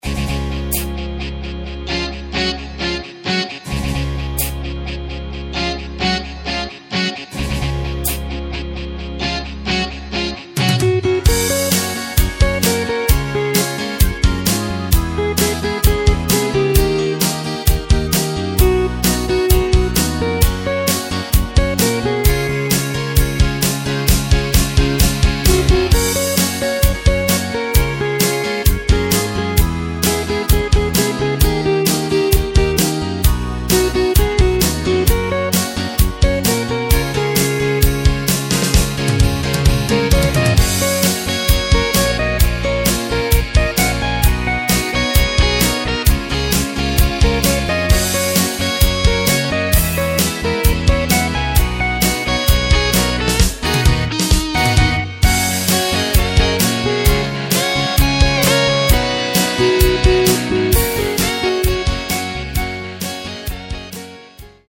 Takt:          4/4
Tempo:         131.00
Tonart:            F#
Deutsch-Rock aus dem Jahr 1977!